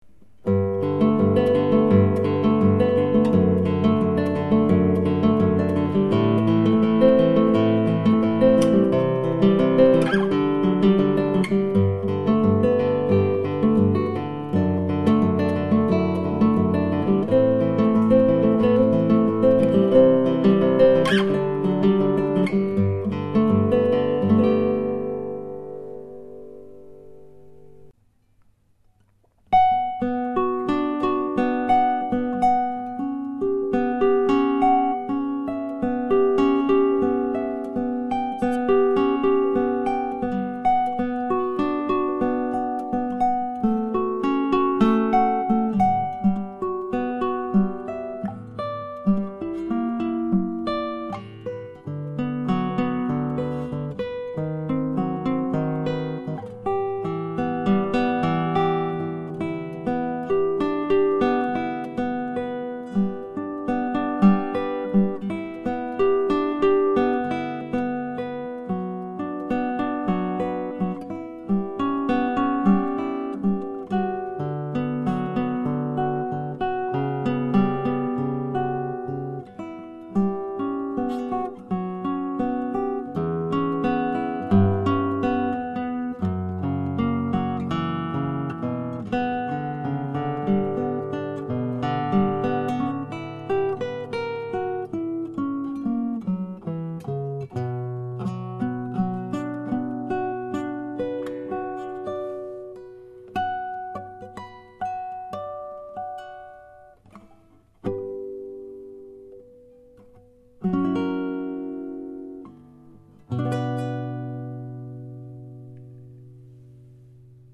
J'ai acheté récemment un enregistreur portable. Le Zoom H4.
L'enregistrement est complètement brut.
Je trouve ça pas mal ! Même si ce n'est pas aussi bien qu'une chaine d'enregistrement classique.